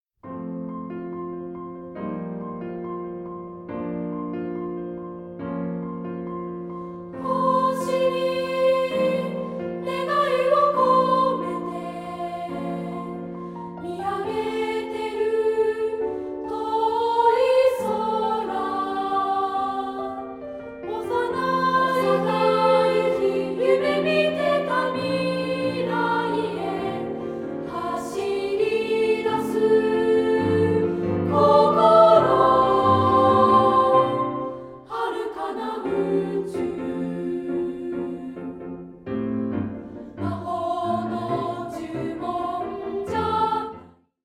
同声2部合唱／伴奏：ピアノ